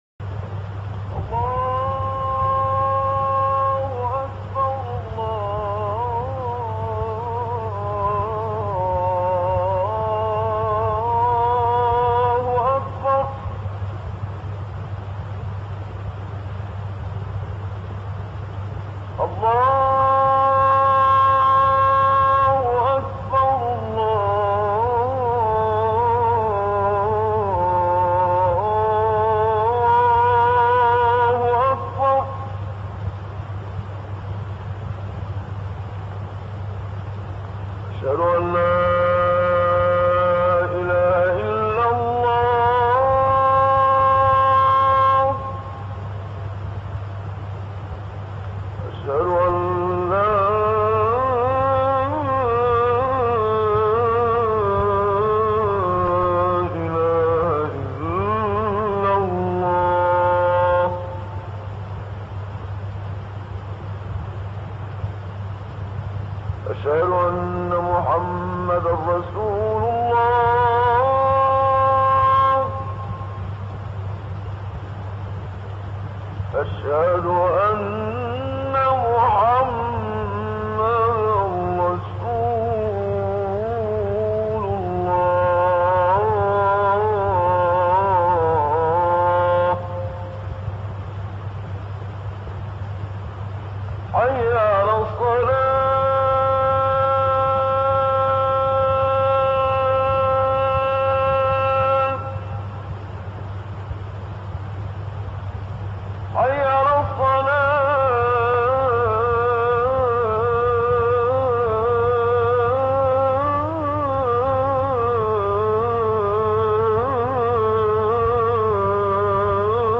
گروه فعالیت‌های قرآنی: گلبانگ اذان، با صدای دلنشین 9 قاری بین‌المللی را می‌شنوید.
اذان محمد صدیق منشاوی